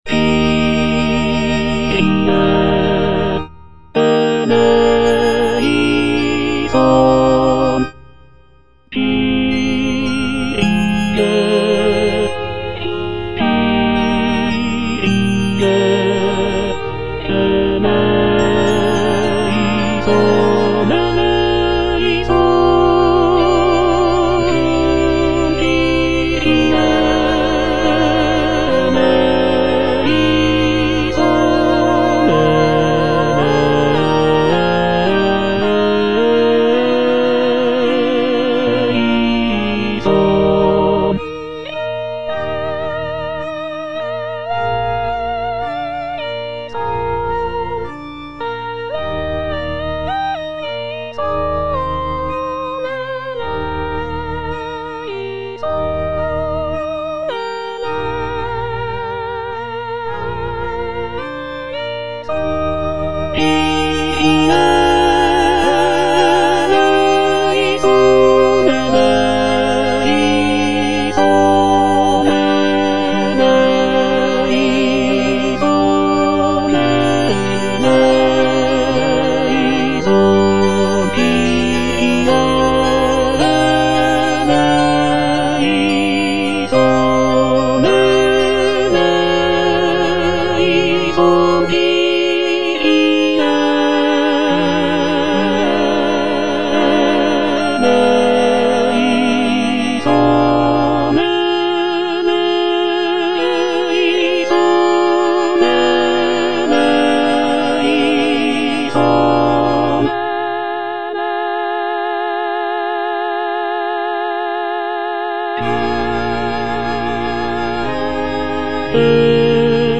Tenor (Emphasised voice and other voices) Ads stop